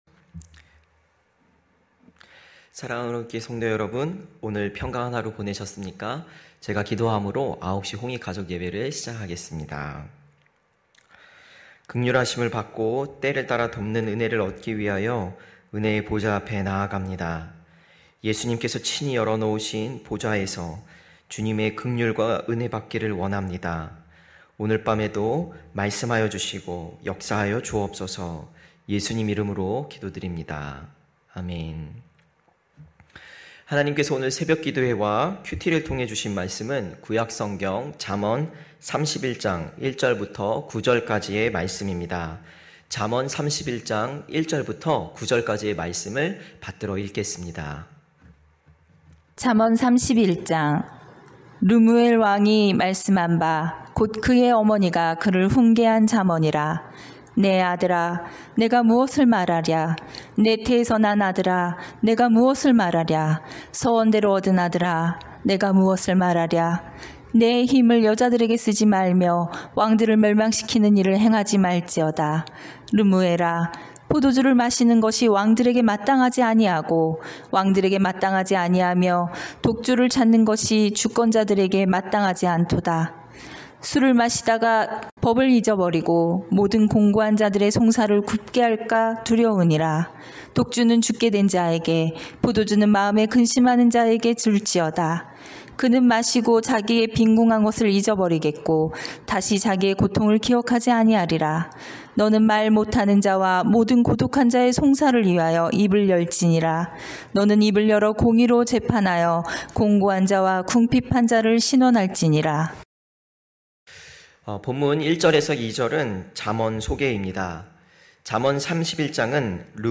9시홍익가족예배(6월29일).mp3